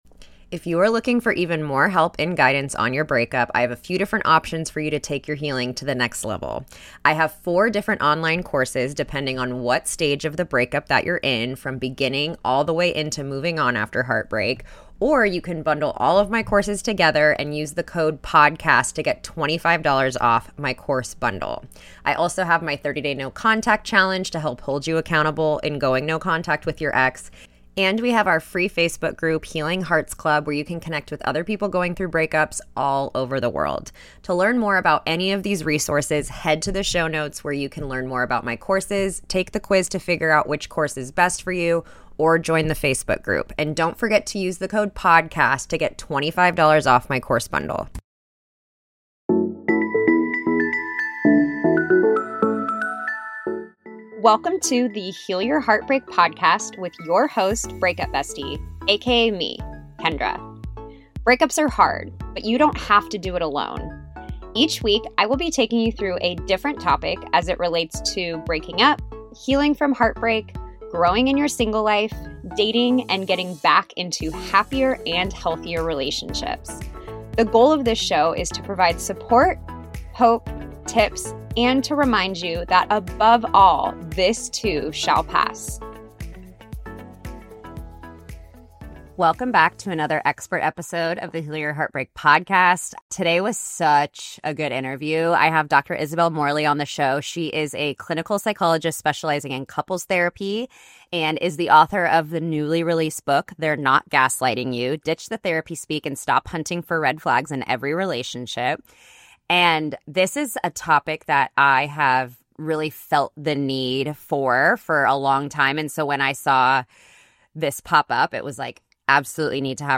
Today I’m taking you solo through a topic that hits close to home—reclaiming the things that feel lost after a breakup.